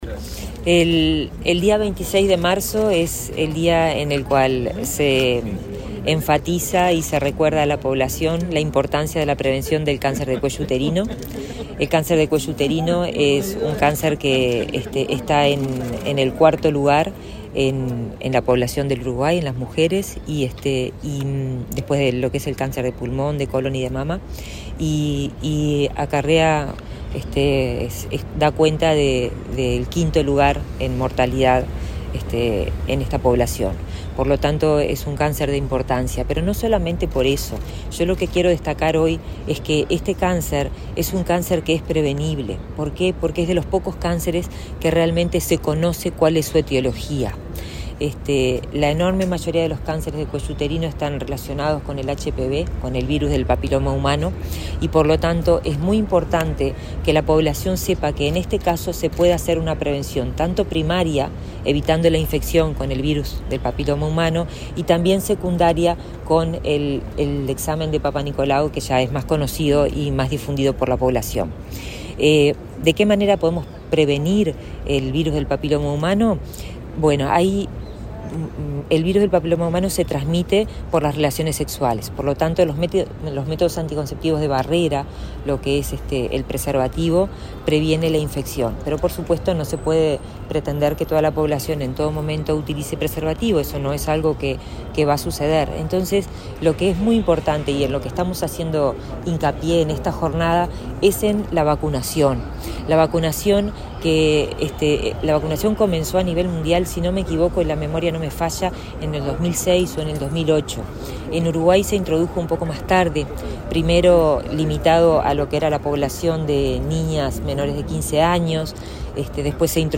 Declaraciones de la ministra de Salud Pública, Karina Rando
En el marco del Día Mundial de Lucha contra el Cáncer de Cuello Uterino, el Ministerio de Salud Pública y el Programa Nacional de Control del Cáncer realizaron una jornada de vacunación contra el virus del papiloma humano en el vacunatorio del Centro Hospitalario Pereira Rossell. La titular de la cartera, Karina Rando, dialogó con la prensa sobre la importancia de prevenir esta enfermedad.